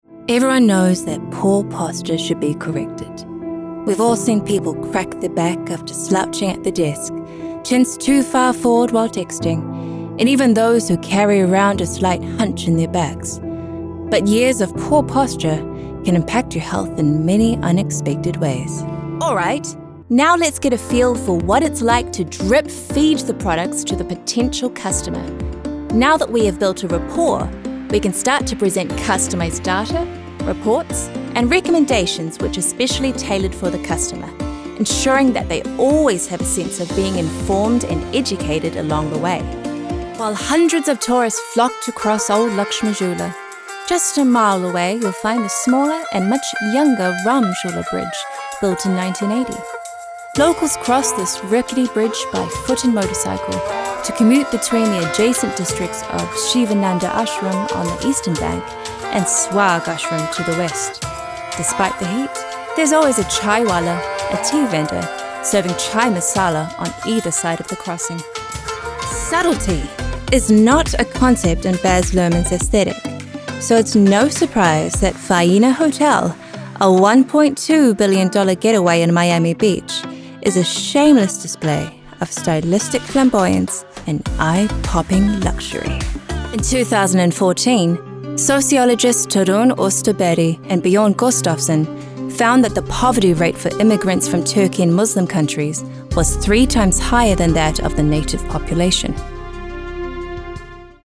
English | New Zealand
NARRATION FILTERS